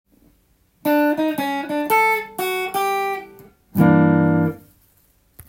マイナー系フレーズ②
やすいフレーズです。クロマチックスケールを使っているので
osyare.ending2.m4a